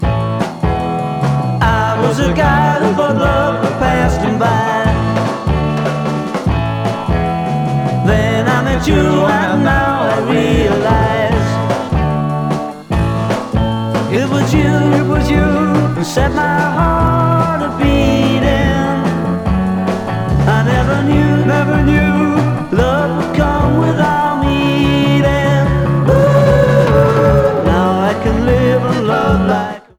UK Acetate